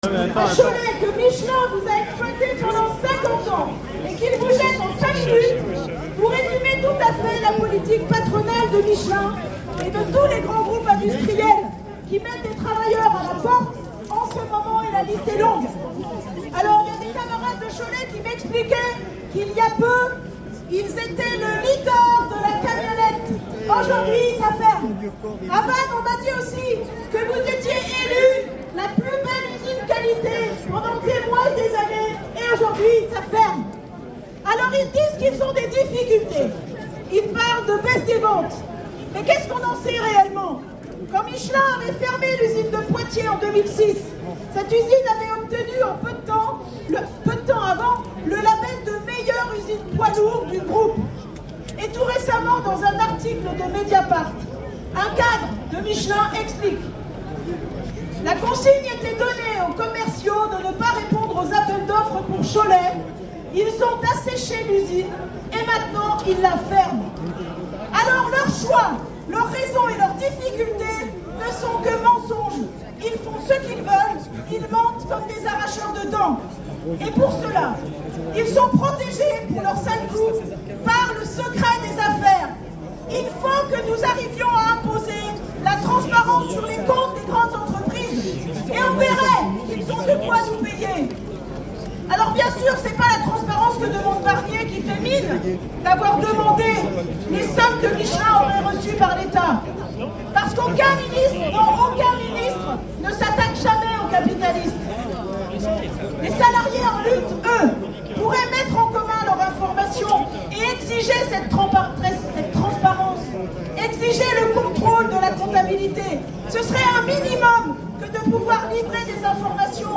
Prise de parole devant le siège social de Michelin à Clermont-Ferrand, après la manifestation des travailleurs des différentes usines Michelin du pays.